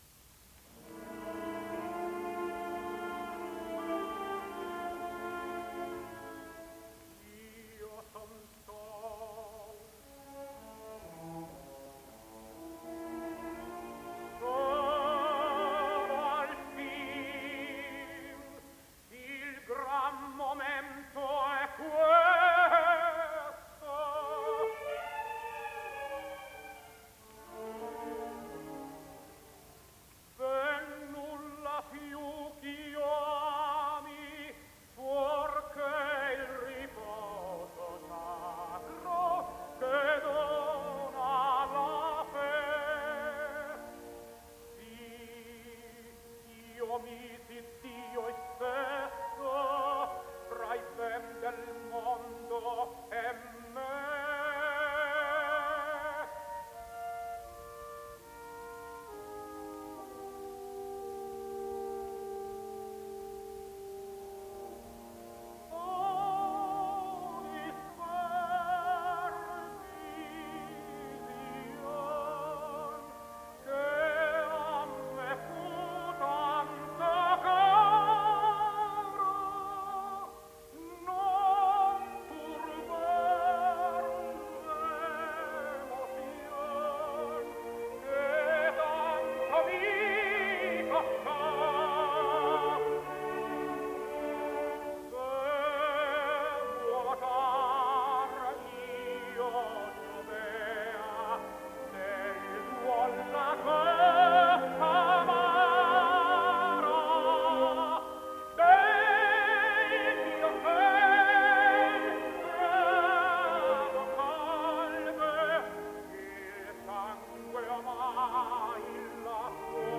Tenore TITO SCHIPA “L’emozione”